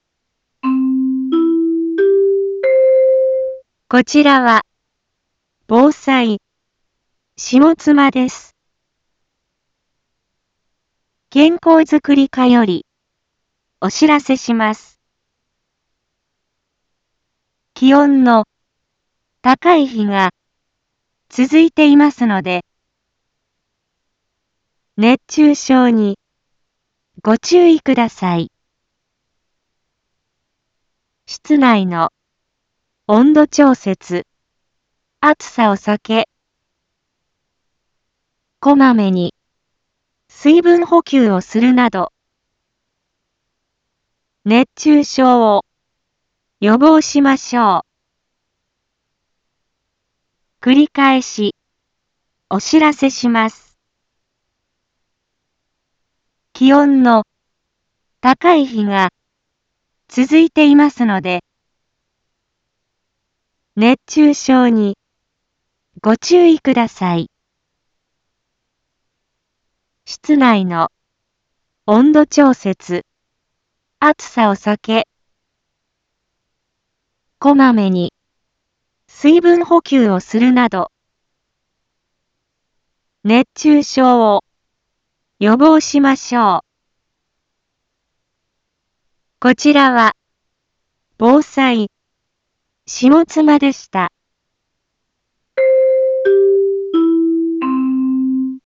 一般放送情報
Back Home 一般放送情報 音声放送 再生 一般放送情報 登録日時：2023-07-03 11:01:42 タイトル：熱中症注意のお知らせ インフォメーション：こちらは、防災、下妻です。